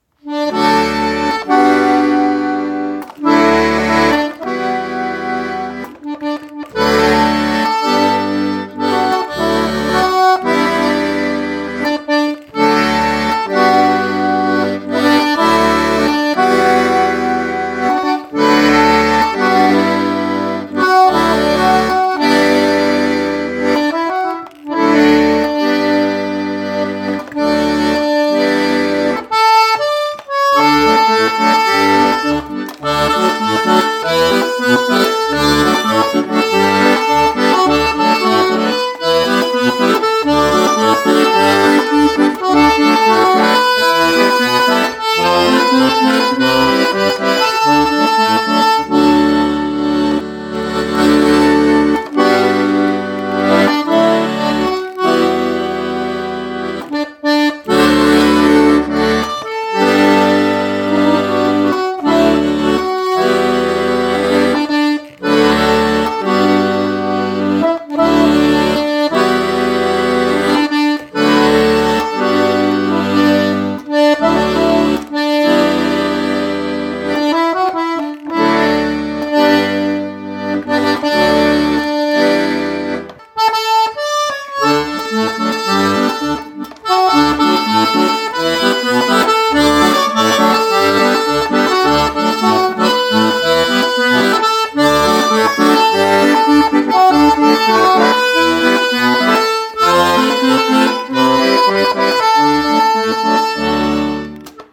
Polkas